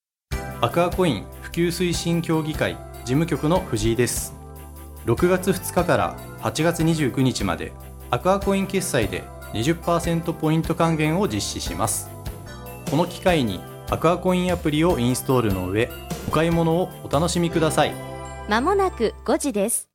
木更津の魅力をPRするCMを放送しています！
出演者：アクアコイン普及推進協議会事務局